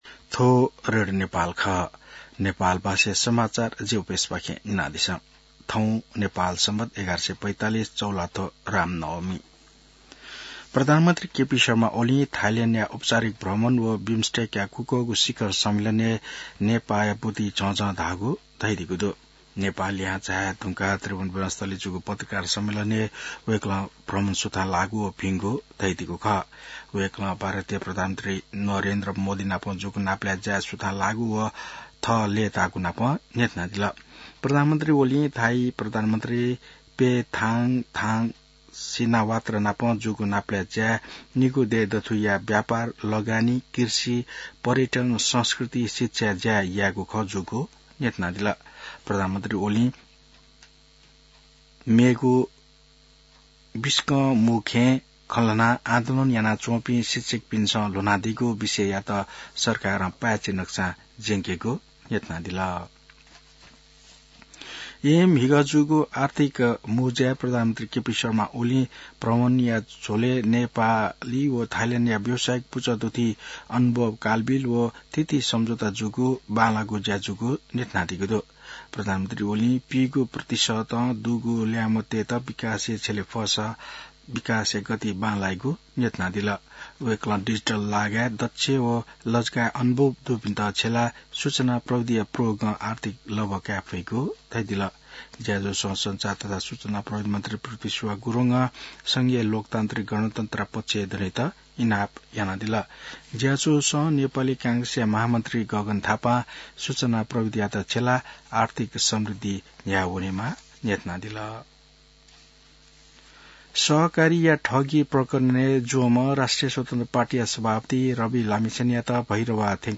नेपाल भाषामा समाचार : २४ चैत , २०८१